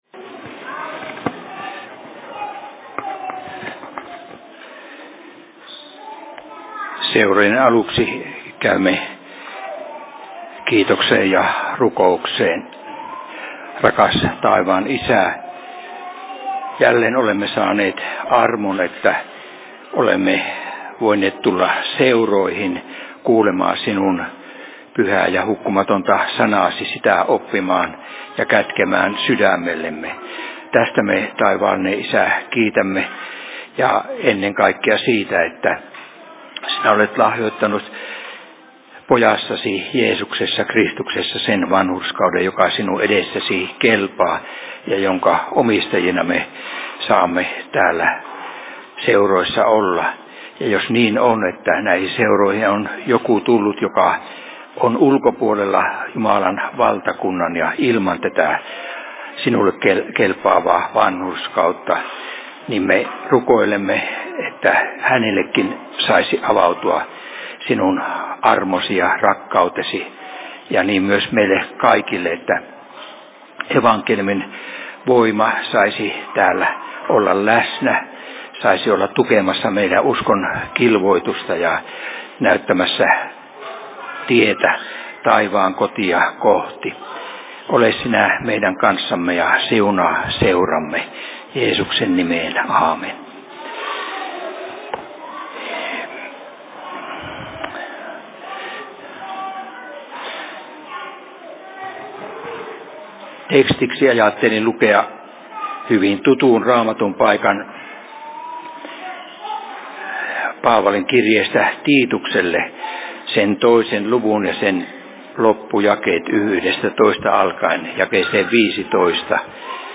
Seurapuhe Tyrnävän RY:llä 29.01.2023 16.00
Paikka: Rauhanyhdistys Tyrnävä